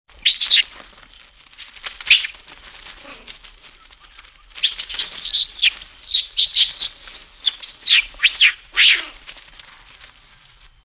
На этой странице собраны звуки макак – от игривого щебета до громких предупредительных сигналов.
Макака кричит с дерева, наверное хочет кушать